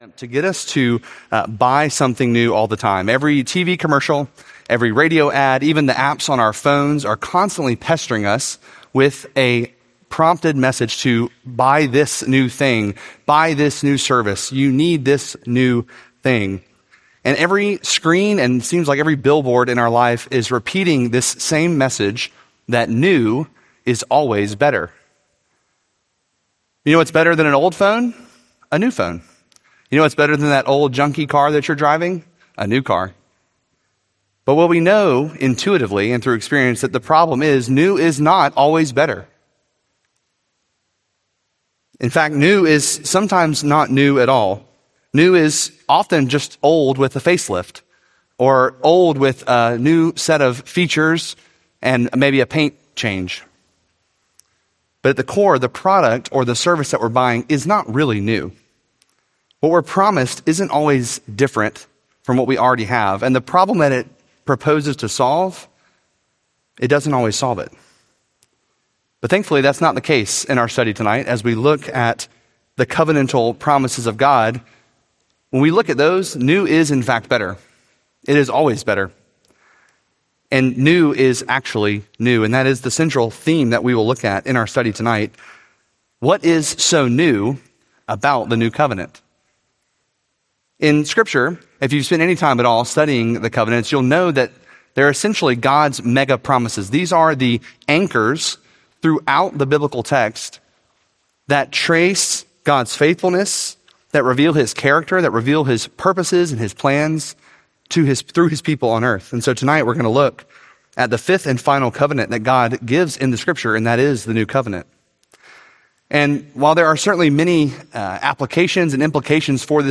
Series: Benediction Evening Service, Guest